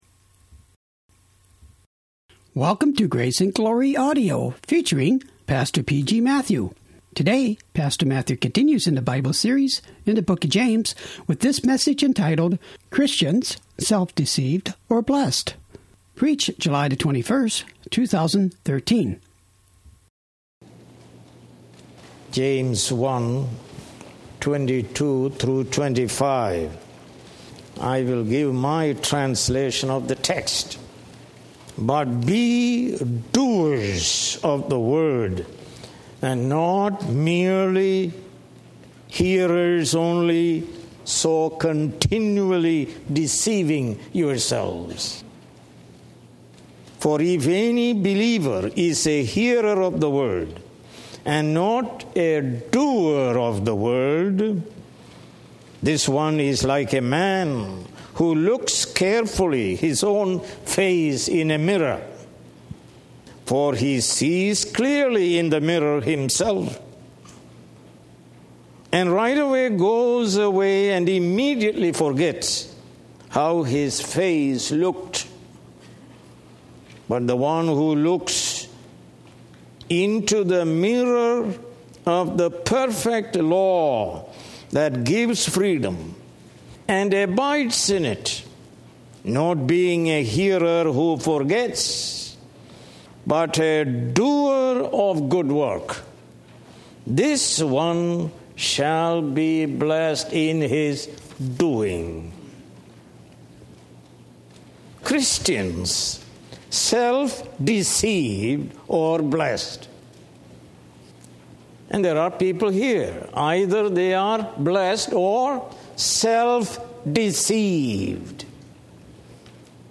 More Sermons From the book of James